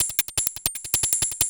Hats 05.wav